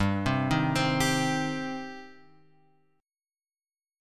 G Suspended 4th Sharp 5th